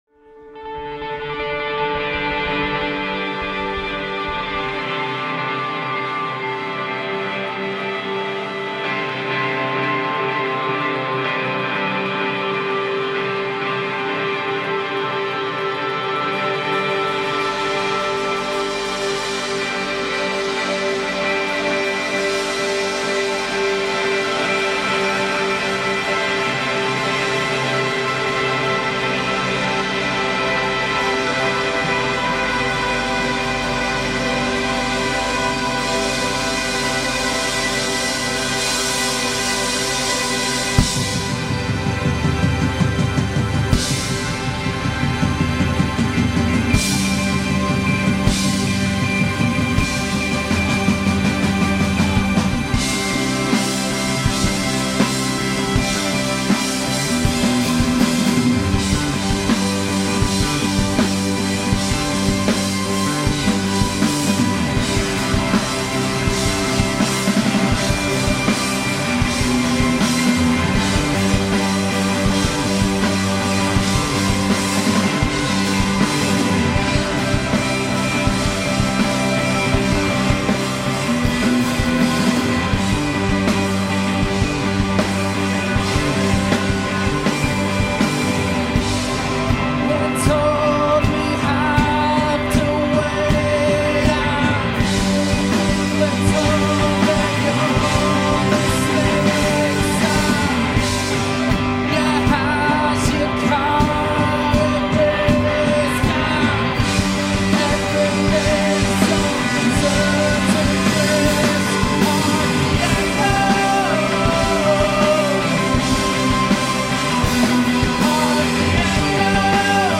post-Rock/Psychedelia
recorded live just a few days ago